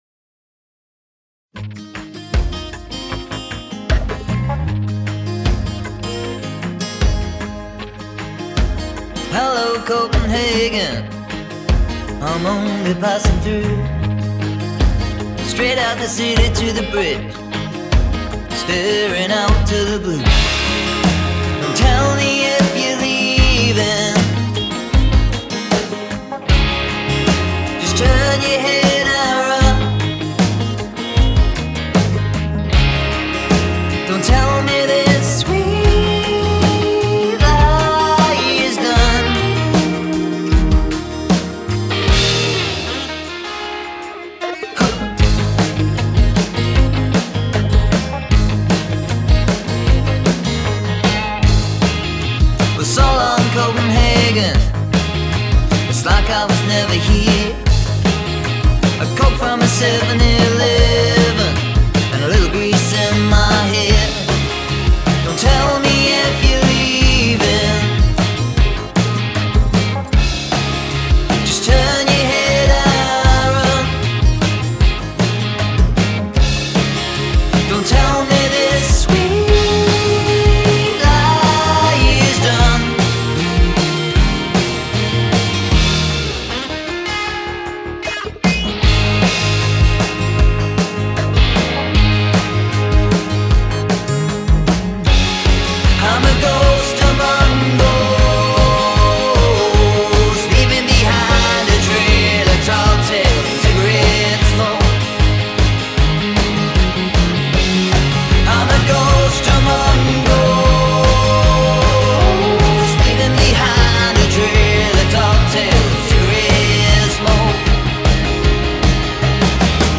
Male Vocal, Electric Guitar, Synth, Bass Guitar, Drums